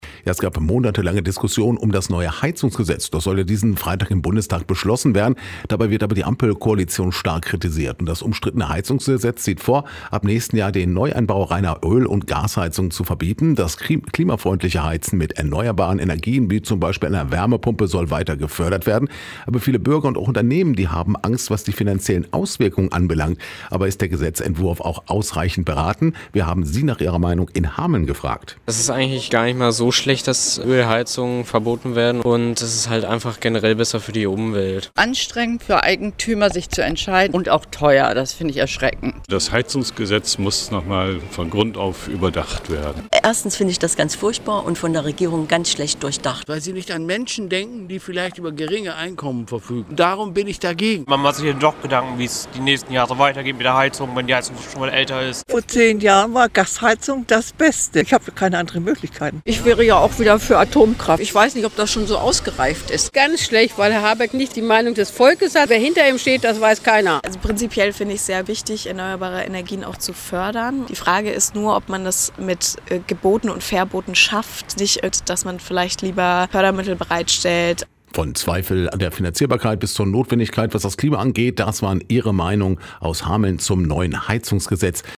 Umfrage: Ihre Meinung zum „Heizungsgesetz“